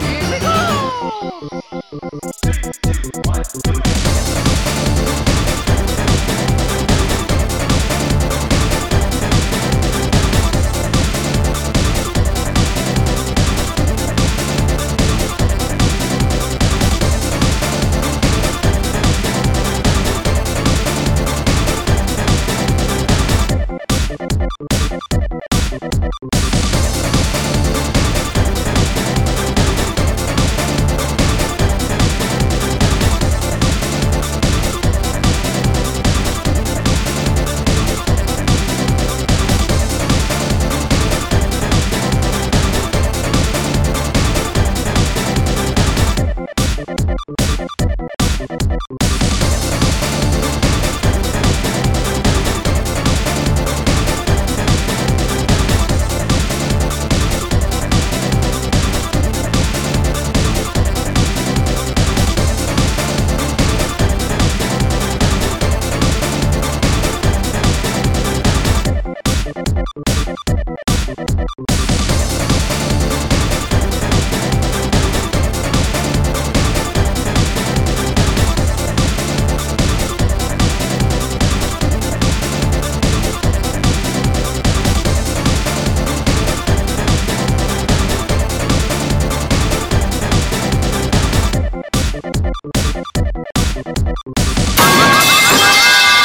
This is a fan made remix of that song.